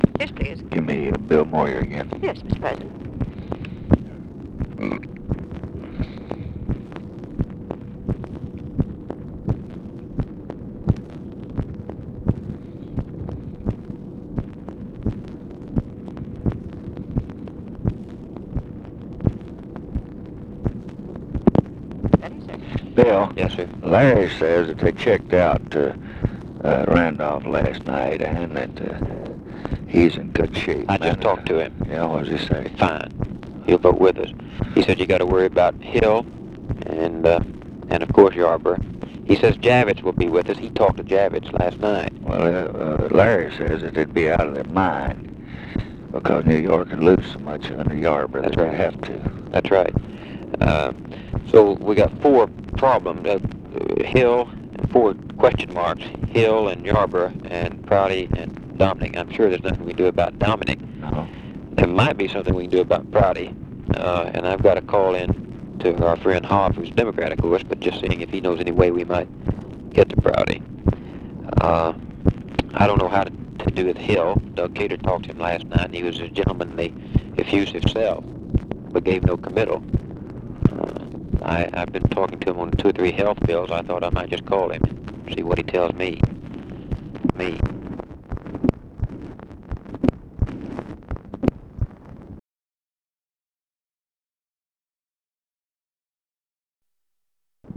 Conversation with BILL MOYERS, April 1, 1965
Secret White House Tapes